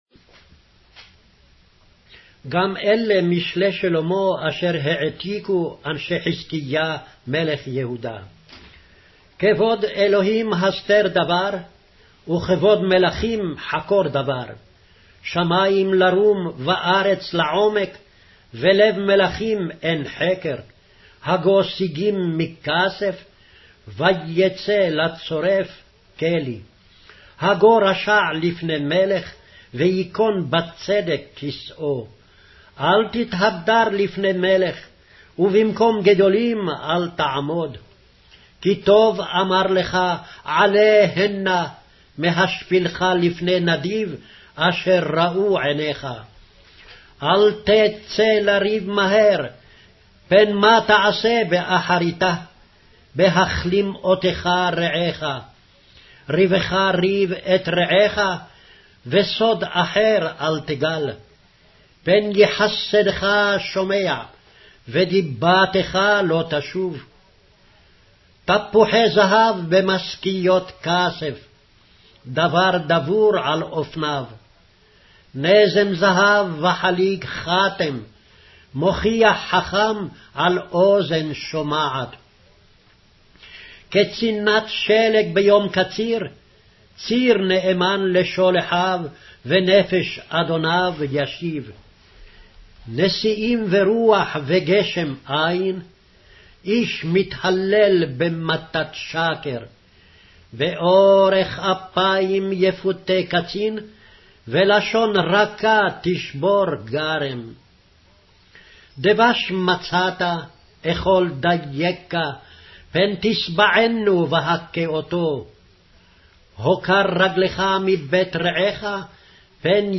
Hebrew Audio Bible - Proverbs 17 in Hcsb bible version